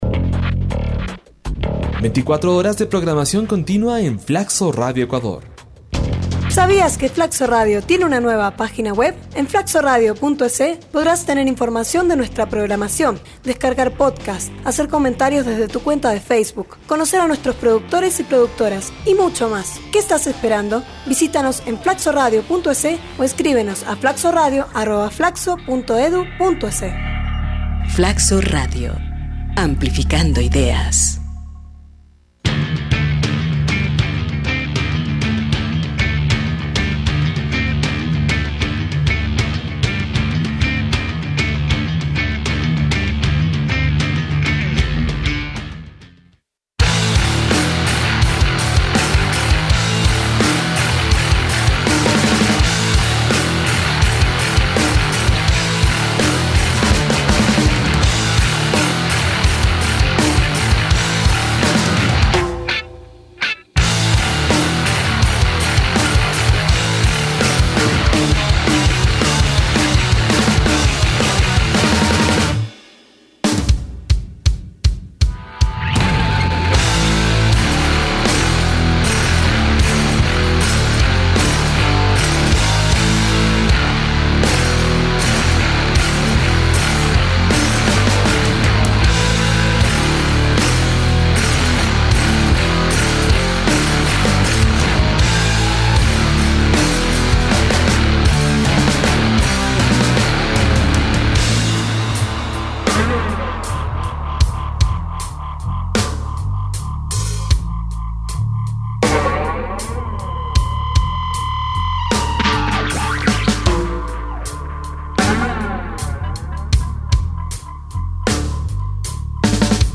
grupo de punk mexicano
rock psicodélico de los 60